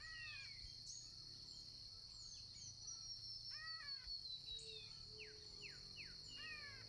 Arañero Silbón (Myiothlypis leucoblephara)
7.12 am. A 1 CUADRA DEL MIRADOR.
Nombre en inglés: White-rimmed Warbler
Localidad o área protegida: Colonia Carlos Pellegrini
Condición: Silvestre
Certeza: Vocalización Grabada
Aranero-Silbon.mp3